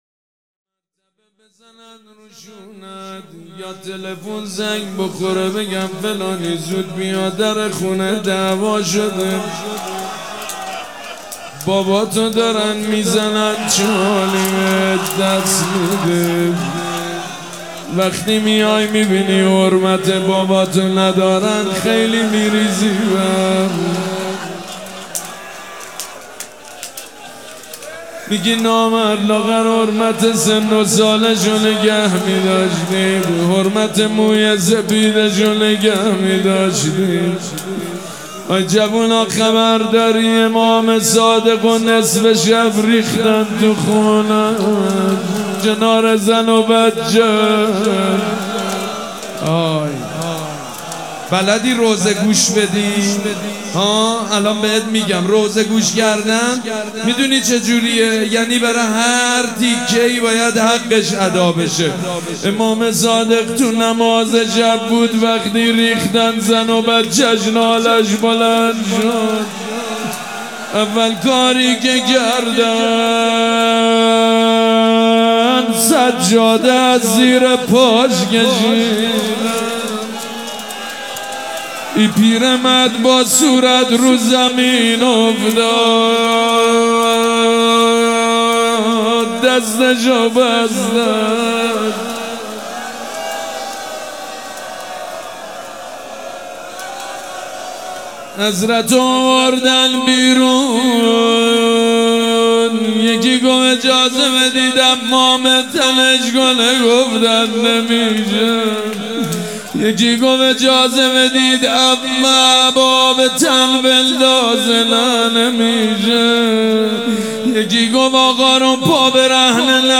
شب شهادت امام صادق علیه السلام
حسینیه ی ریحانه الحسین
روضه